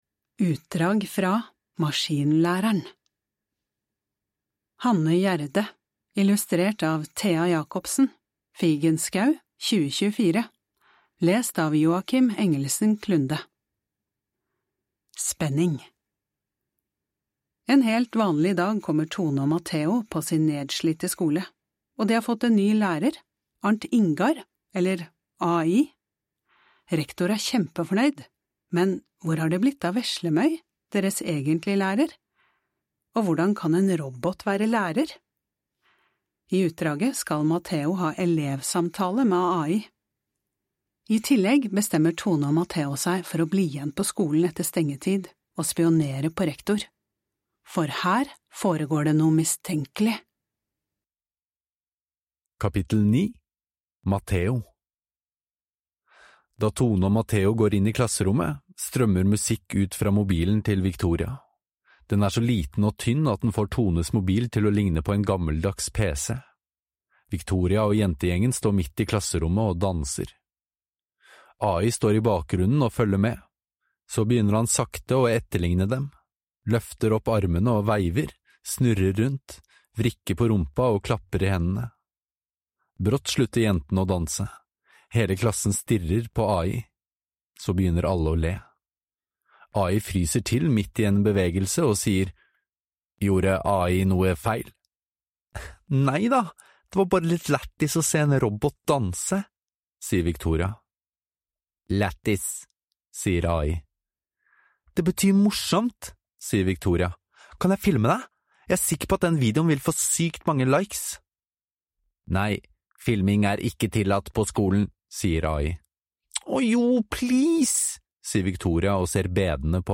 Lån hele lydboka fra Tibi!